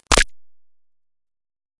描述：与"Attack Zound05"相似，但有一个长的衰减，在衰减结束时有一个奇怪的声音效果。这个声音是用Cubase SX中的Waldorf Attack VSTi制作的。
Tag: 电子 SoundEffect中